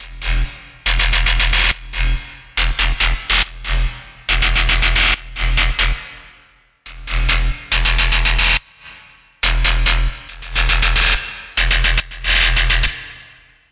描述：胡萝卜攫取的Wav文件
Tag: 牛蒡 胡萝卜 食品